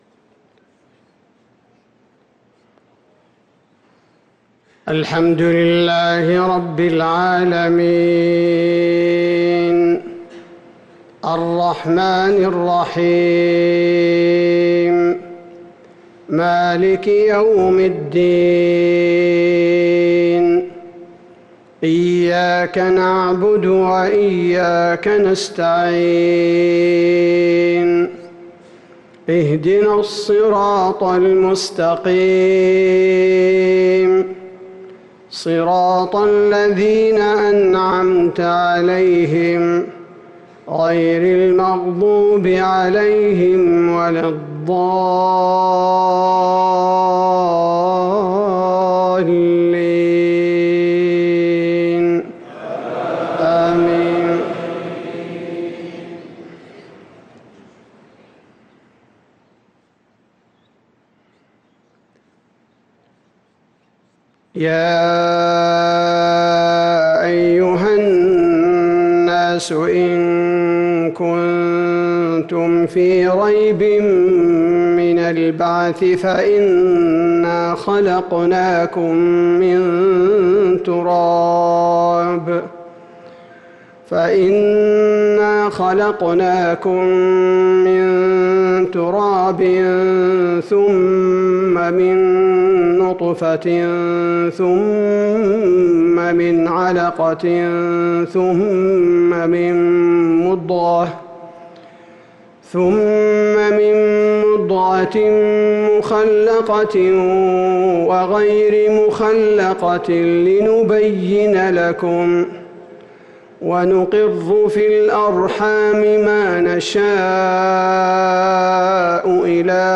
صلاة المغرب للقارئ عبدالباري الثبيتي 14 محرم 1445 هـ
تِلَاوَات الْحَرَمَيْن .